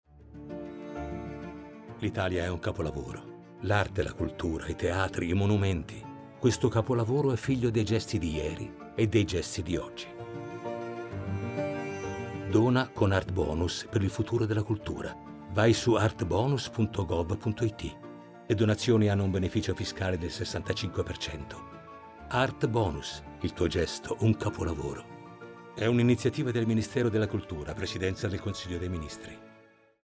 Lo spot televisivo
La curiosità del bambino lo porta a chiedere all’adulto informazioni sull’opera che stanno osservando.